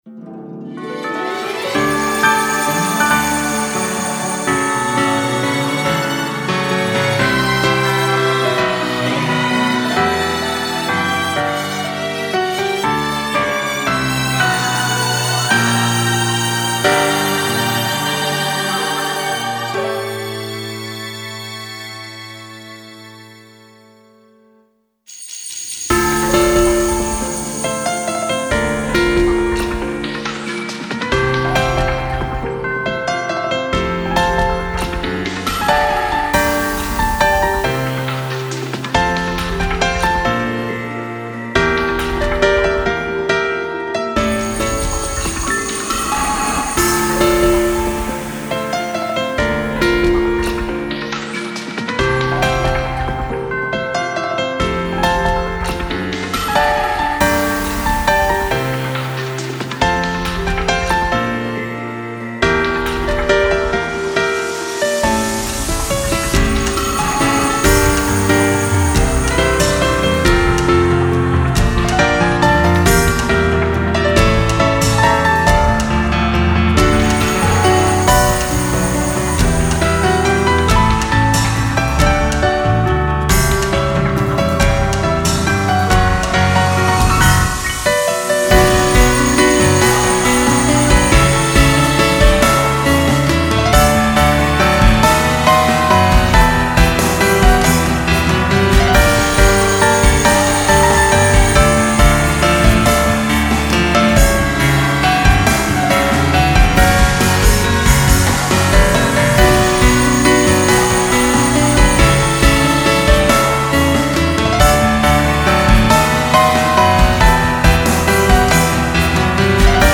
dolce instrumental